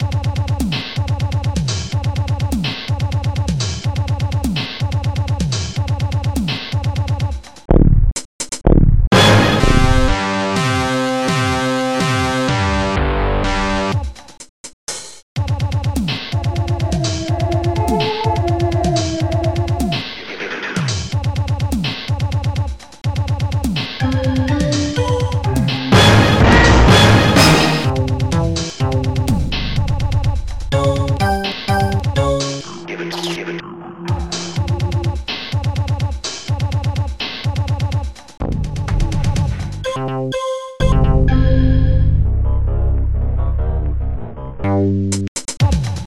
Protracker and family